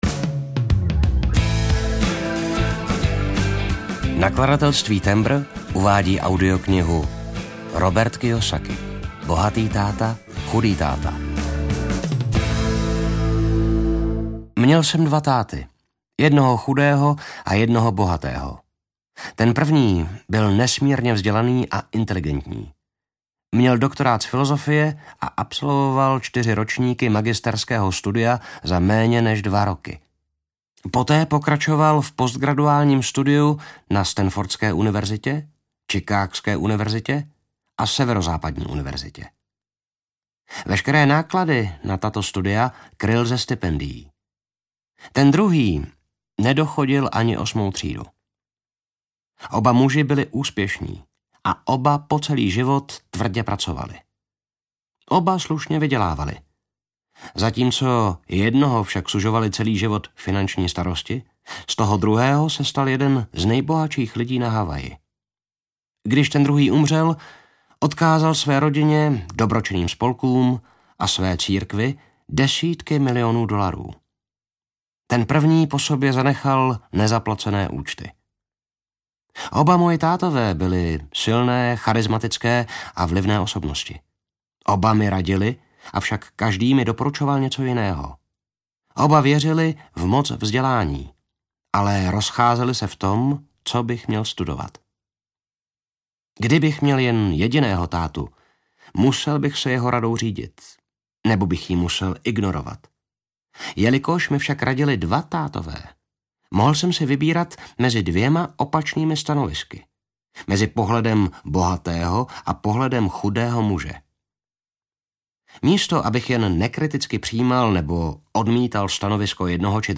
Bohatý táta, chudý táta audiokniha
Ukázka z knihy